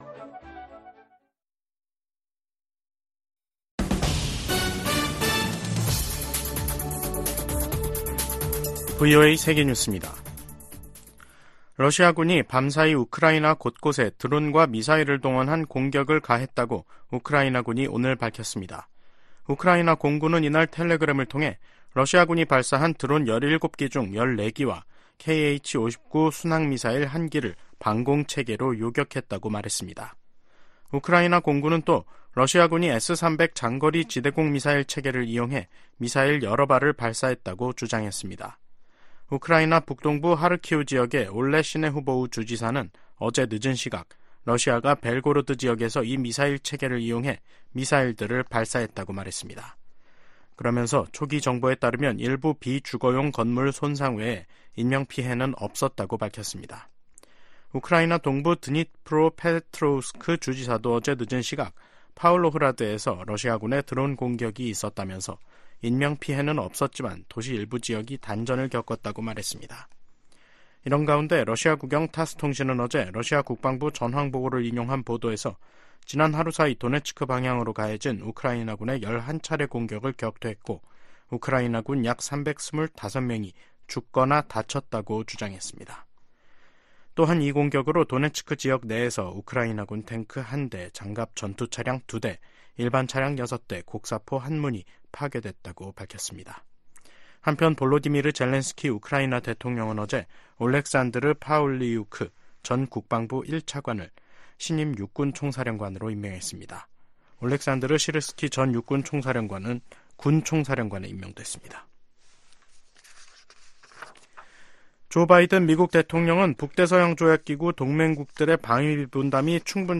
VOA 한국어 간판 뉴스 프로그램 '뉴스 투데이', 2024년 2월 12일 2부 방송입니다. 북한 국방과학원이 조종 방사포탄과 탄도 조종체계를 새로 개발하는 데 성공했다고 조선중앙통신이 보도했습니다. 지난해 조 바이든 행정부는 총 11차례, 출범 이후 연간 가장 많은 독자 대북제재를 단행한 것으로 나타났습니다. 미국과 한국·일본의 북한 미사일 경보 정보 공유는 전례 없는 3국 안보 협력의 상징이라고 미 국방부가 강조했습니다.